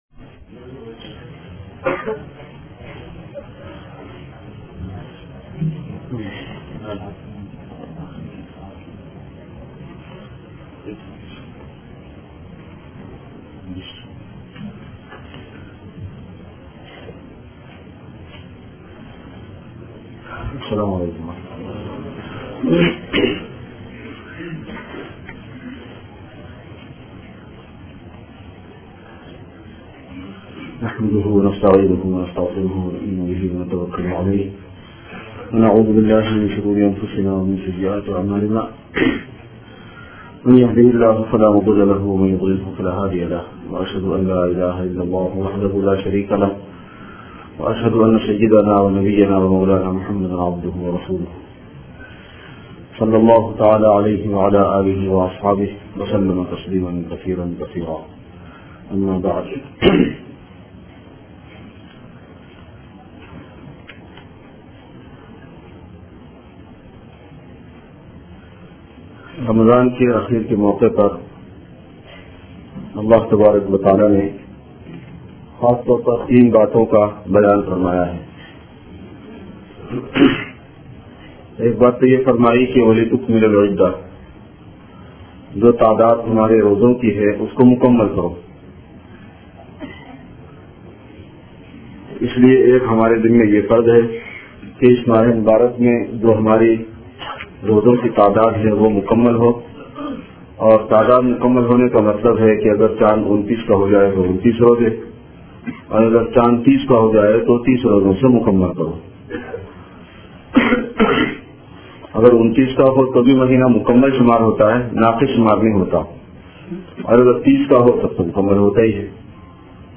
Khutbat e Juma 20-Oct-2006